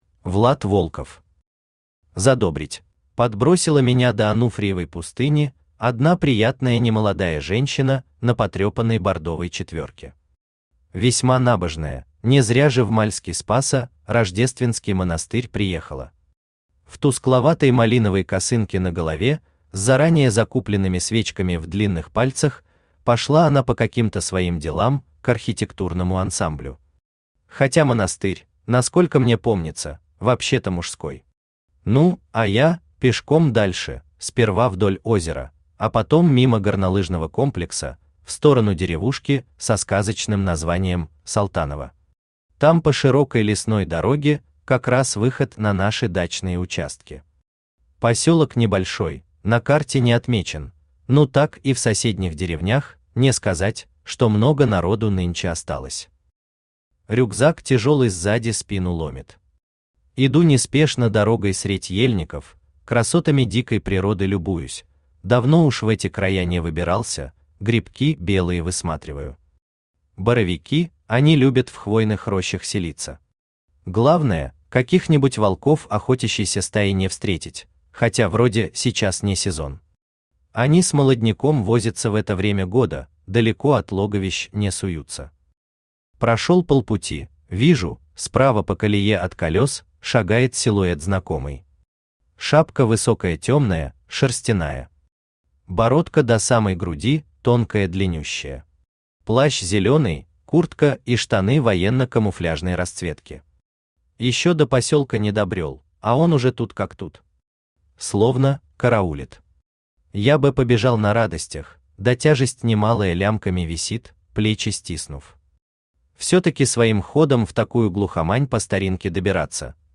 Aудиокнига Задобрить Автор Влад Волков Читает аудиокнигу Авточтец ЛитРес.